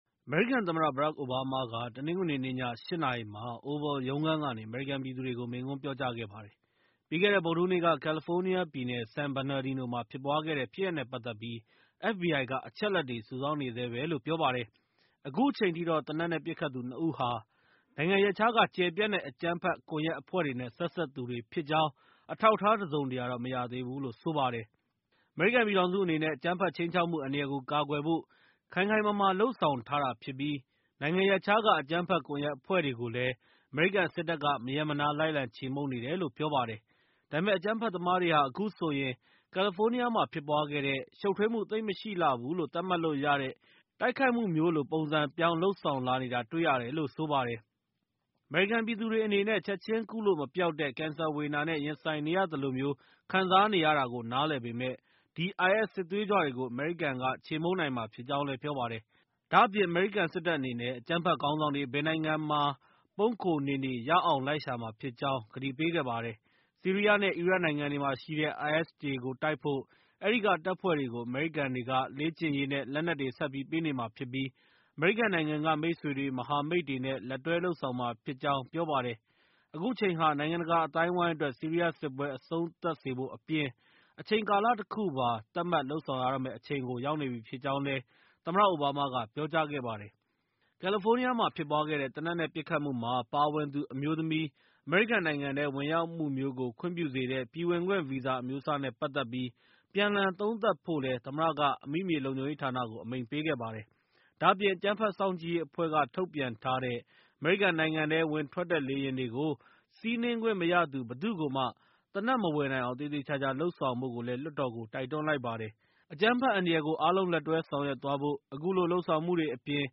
သမ္မတ အိုဘားမား မိန့်ခွန်း